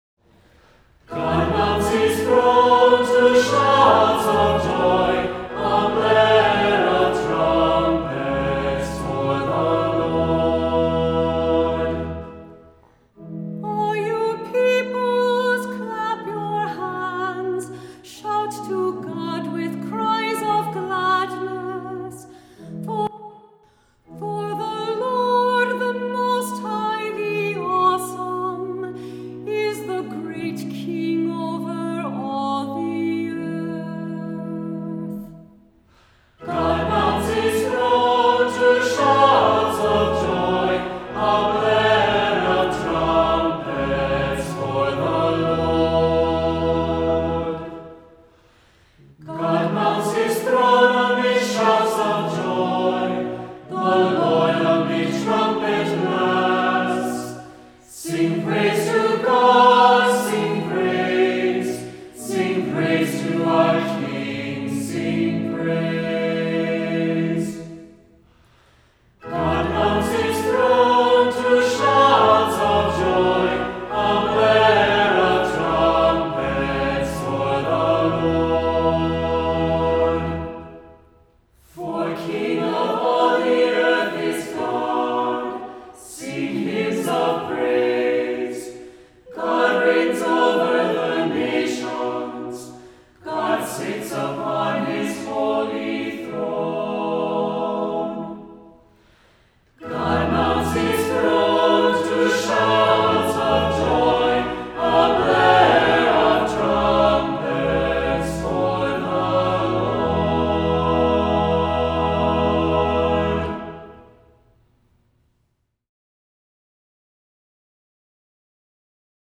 Voicing: Cantor,SATB, assembly